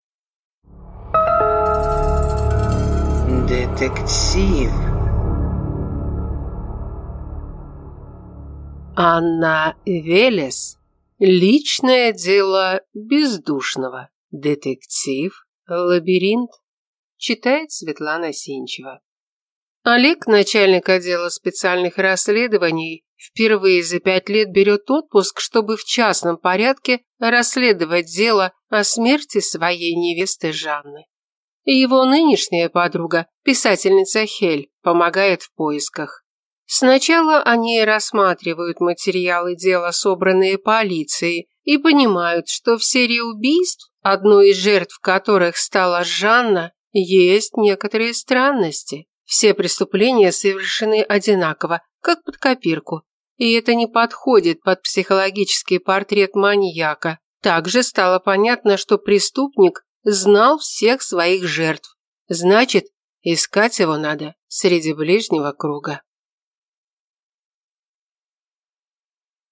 Аудиокнига Личное дело Бездушного | Библиотека аудиокниг